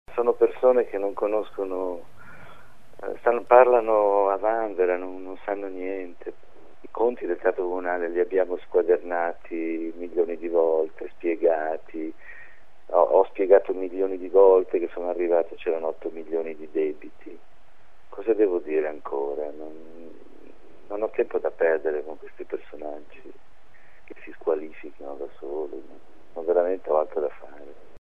“Parlano a vanvera, non sanno niente”, ha replicato ai nostri microfoni il sovrintendente.